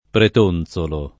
[ pret 1 n Z olo ]